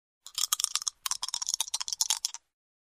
Звуки игральных костей
На этой странице собраны разнообразные звуки игральных костей: от легкого потрясывания в руке до звонкого удара о стол.